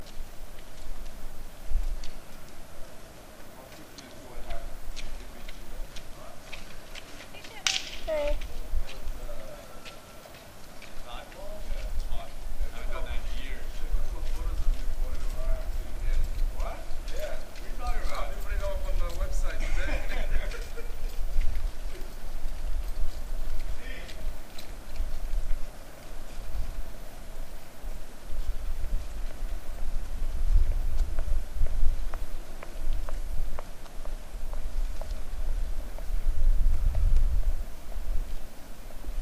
Field Recording 3
Outside the student center, 9:30 am, 2/16